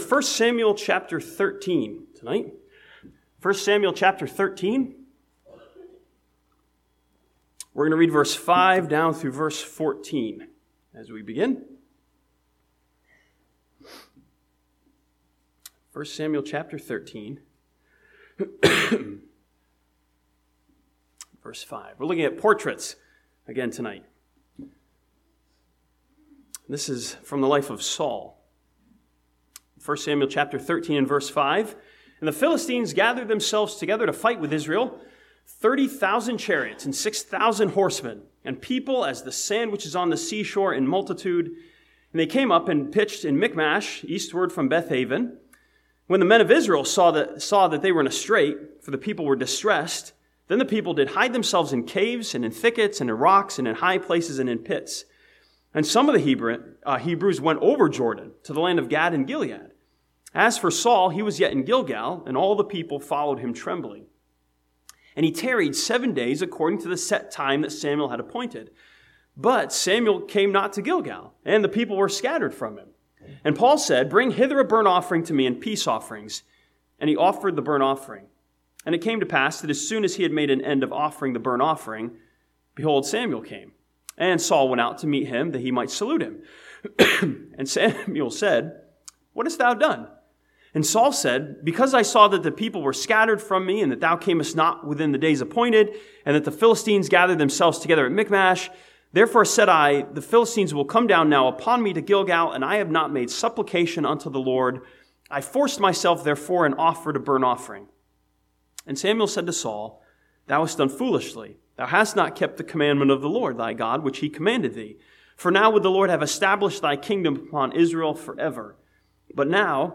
This sermon from 1 Samuel chapter 13 studies one of King Saul's decisions and excuses as a portrait of rationalization.